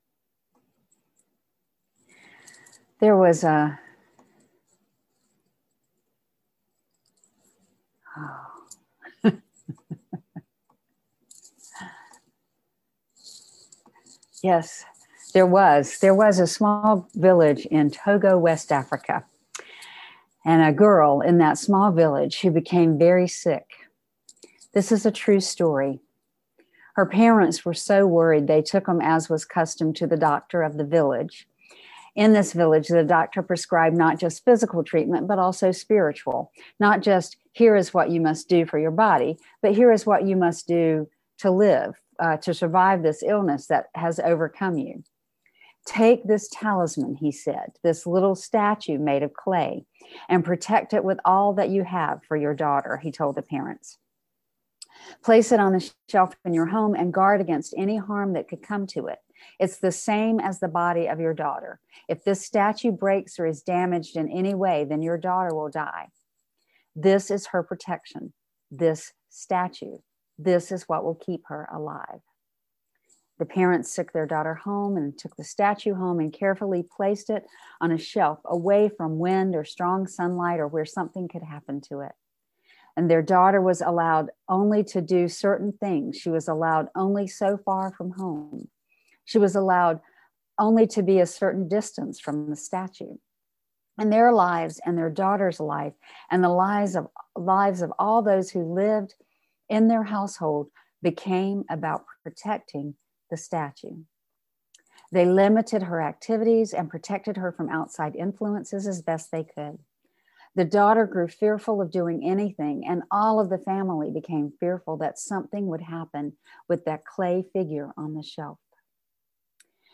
Using a poignant Togolese folk tale about a girl restricted by the need to protect a fragile clay statue, this sermon explores how fear and preservation can stifle human potential.